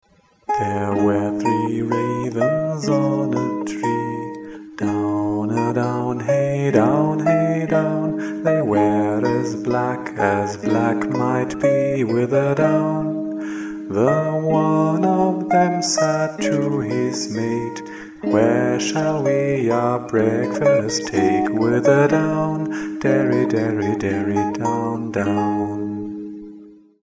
An einer Stelle habe ich die Melodie modifiziert, weil das Original das Klangspektrum meines Scheitholts sprengen und das meiner Stimme massiv an die Grenze treiben würde :)
Eine schnelle, nicht ganz perfekte Aufnahme (der ersten englischen Zeile, nicht ganz identisch mit der verlinken Fassung) findest du
Ist aber sehr schön geworden für eine schnelle Aufnahme, jetzt kann ich mir was drunter vorstellen :)